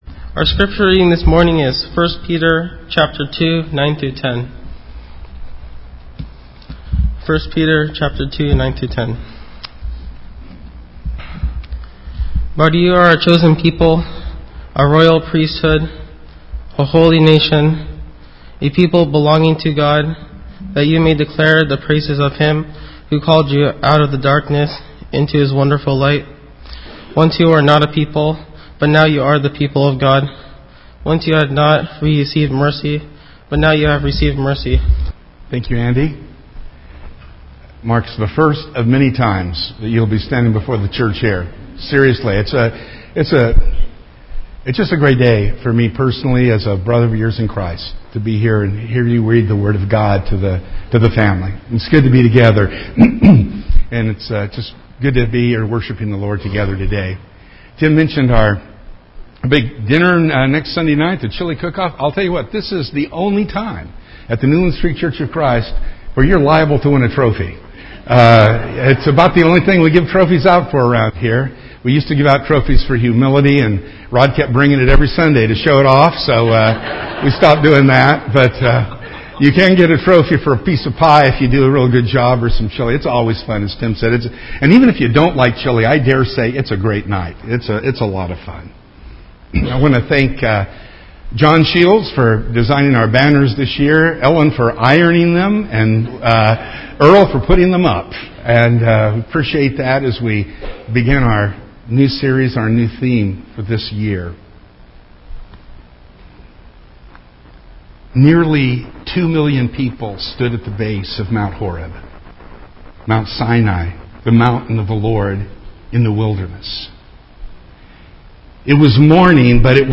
This is the first part of our new sermon series “He Can and He Will”.